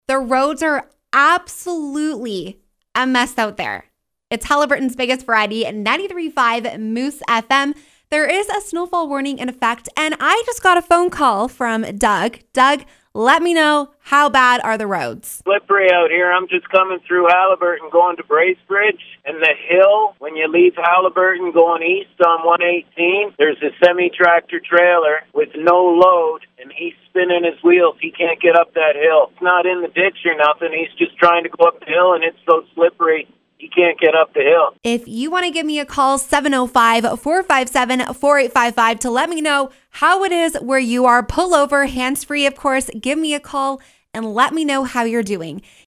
road-report.mp3